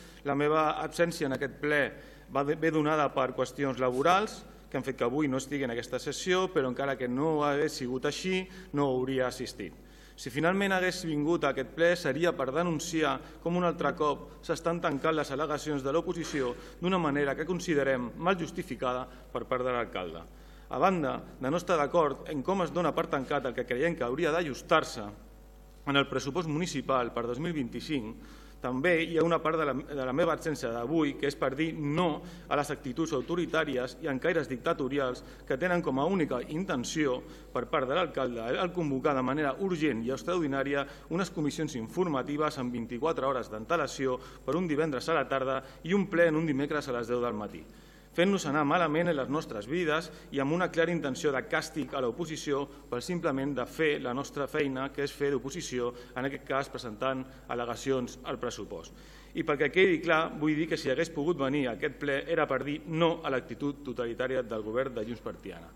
El ple ha celebrat aquest dimecres 22 de gener una sessió extraordinària per desestimar les al·legacions presentades per part dels portaveus d’ERC i el PSC, Alex Van Boven i Miquel Santiago, a l’aprovació inicial del pressupost 2025.
Van Boven no ha pogut assistir al ple per qüestions laborals, però segons ha transmès a través d’un escrit llegit pel regidor no adscrit, tampoc hauria vingut per denunciar com s’ha convocat el ple i la manera com es tanquen les al·legacions de l’oposició: